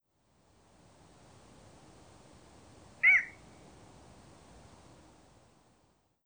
Garza pequena
Ixobrychus minutus
Avetorillo.wav